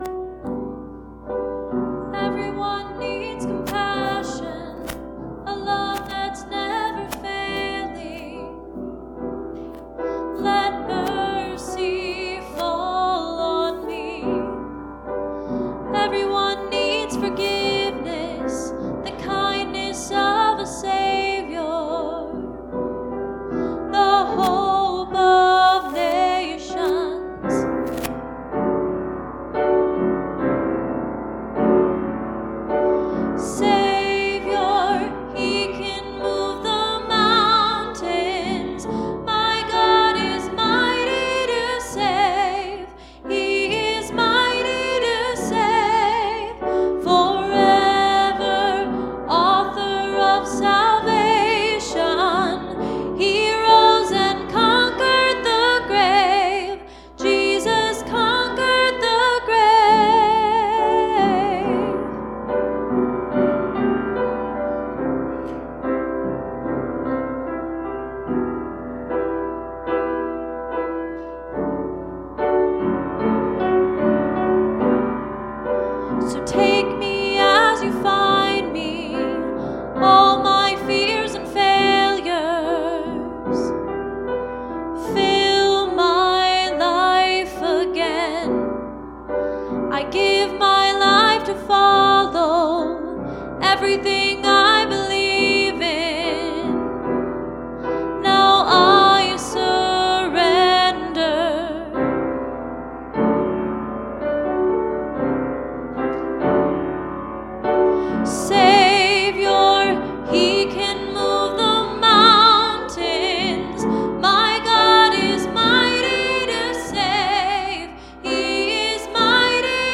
Please, click the arrow below to hear this week's service.